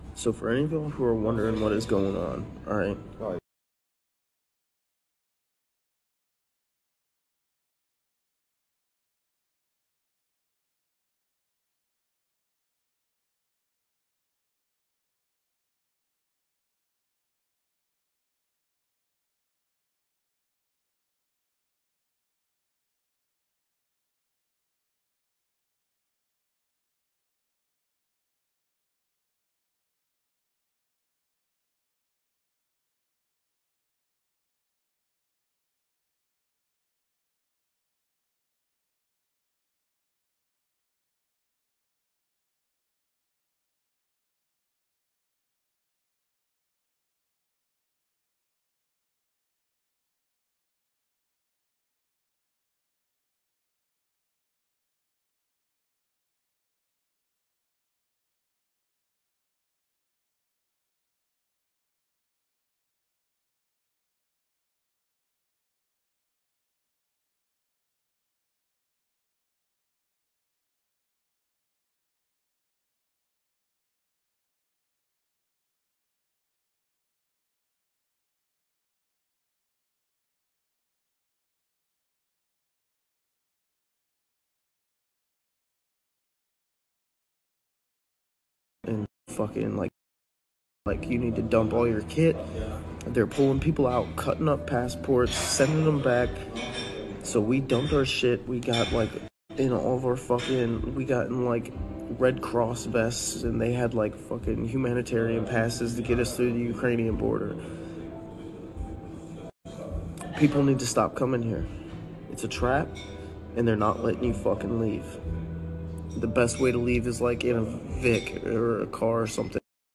Ein britischer Kämpfer spricht über riesige Verluste unter US-amerikanischen und britischen Söldnern und von mieser Behandlung durch die Ukrainer.